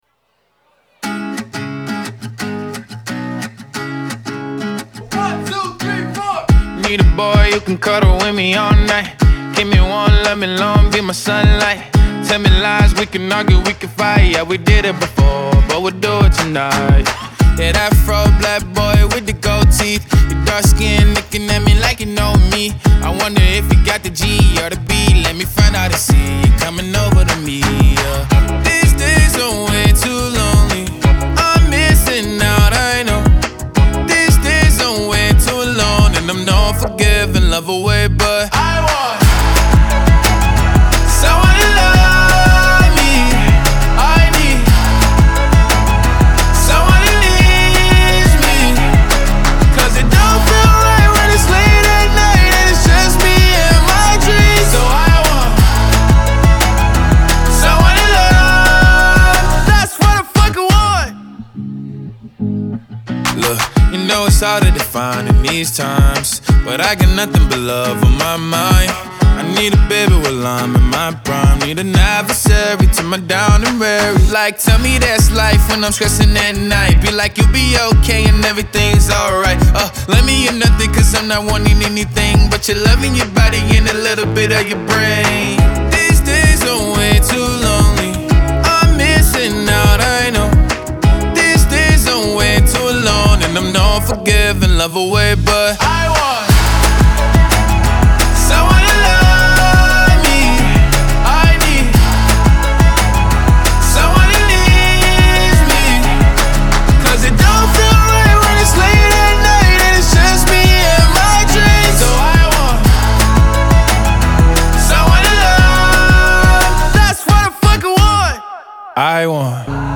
Genre : Hip-Hop